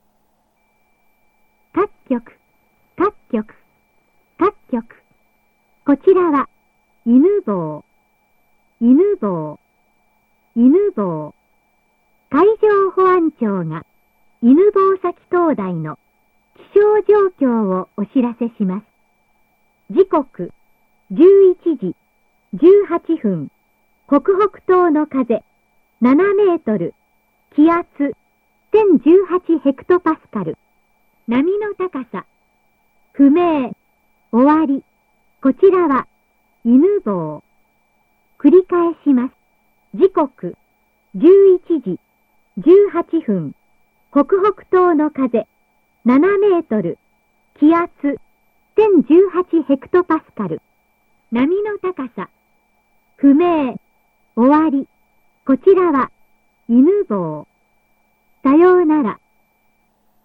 船舶気象通報とは、灯台放送とも呼ばれ、全国29箇所の灯台等で観測した気象現況を、西側の通報箇所から順に1時間に1回、中短波無線（1670.5kHz）を使用して音声でお知らせしていたものです。
平成28年（2016年）9月30日11時23分10秒から約1分間、中短波（1670.5kHz）船舶気象通報「いぬぼう」は最後の通報（放送）を行い、長年にわたる業務を終了しました。